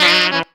HARM RIFF 11.wav